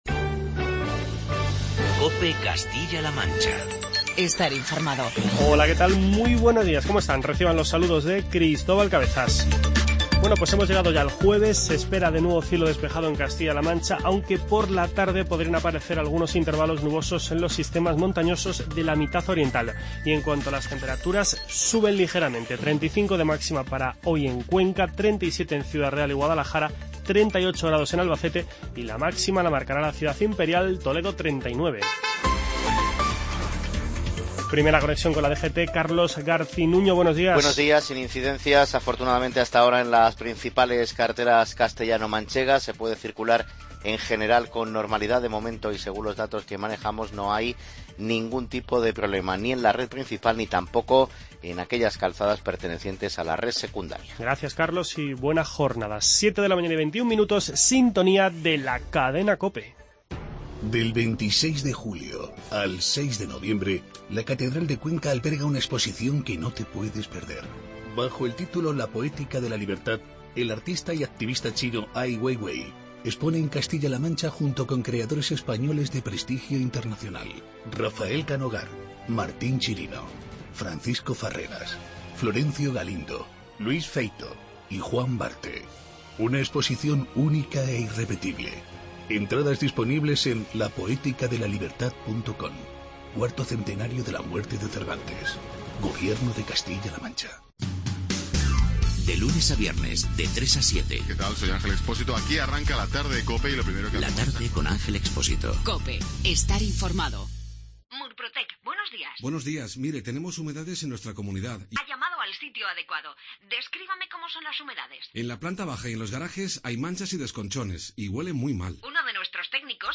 Informativo regional
Comenzamos este espacio con las palabras del portavoz del Gobierno de Castilla-La Mancha, Nacho Hernando.